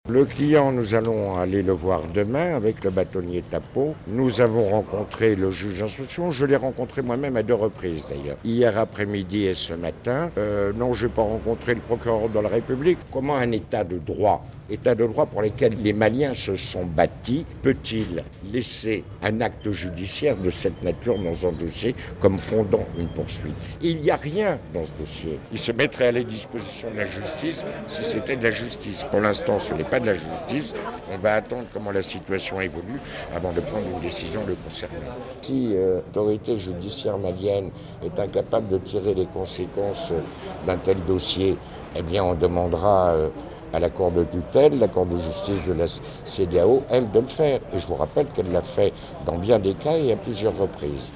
Affaire dite de tentative de déstabilisation de la Transition : les avocats de l’ex-Premier ministre Boubou Cissé doutent de la capacité de la justice malienne à juger ce dossier. Ils l’ont annoncé ce vendredi 08 janvier 2021, lors d’une conférence de presse à Bamako.